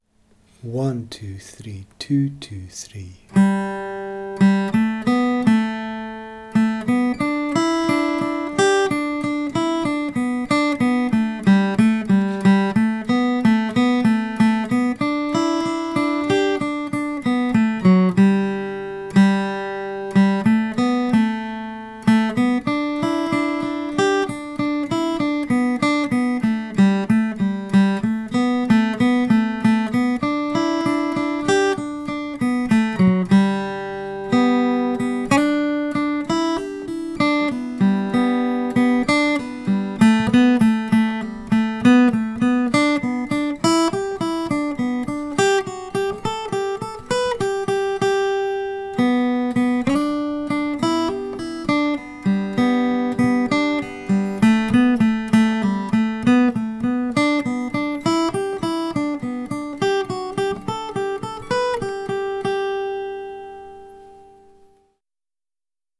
The Practice Audio Tracks & Notation/Tablature for this session are available to download from the ‘Exercise Files’ tab at the top of the page.
The-Kesh-Jig-Melody.wav